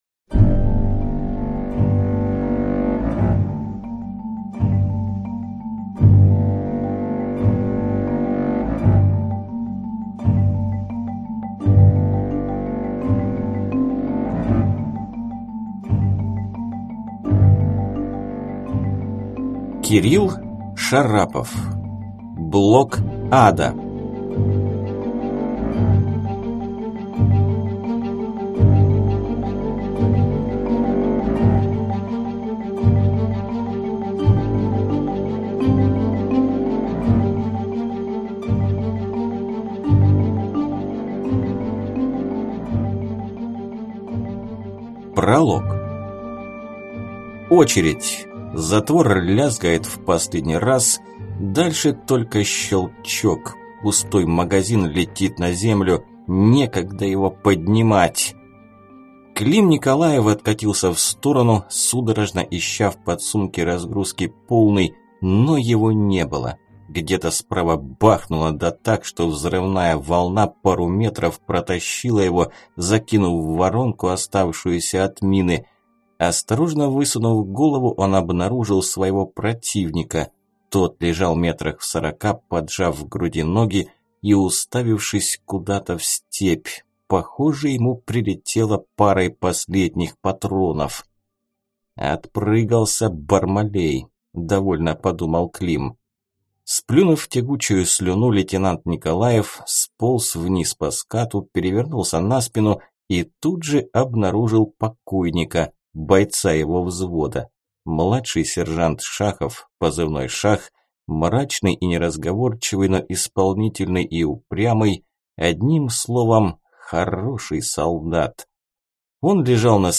Aудиокнига БлокАда